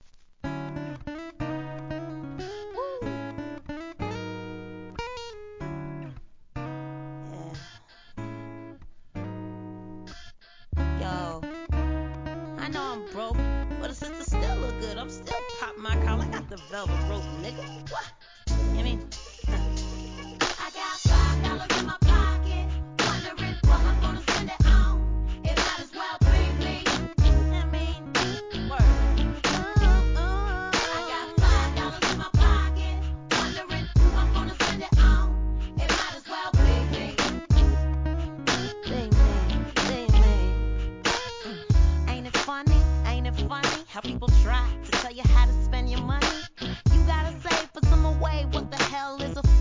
HIP HOP/R&B
2004年、アコースティックの心地よいトラックに爽快なコーラスで仕上げたR&B♪